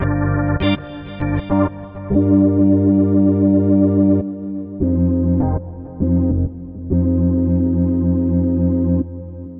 Tag: 100 bpm Chill Out Loops Organ Loops 1.62 MB wav Key : Unknown